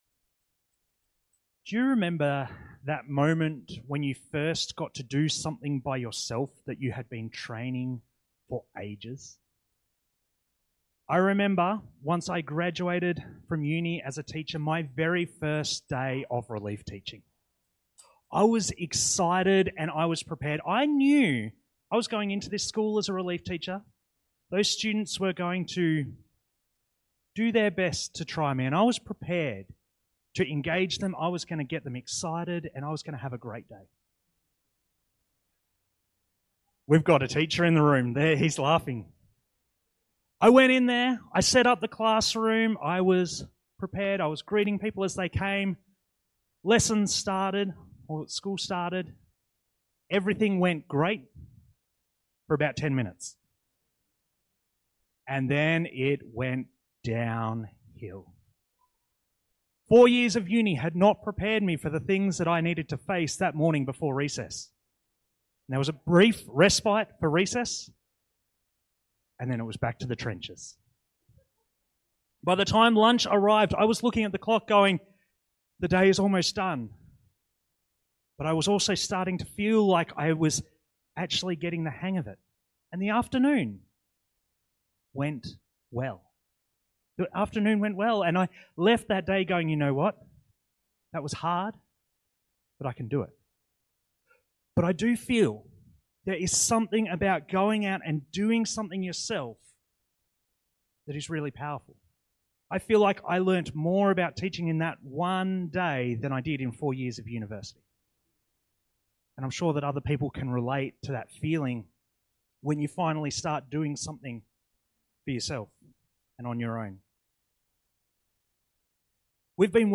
Listen to all the latest sermons from the team at Alice Springs Baptist Church, located in the heart of Australia.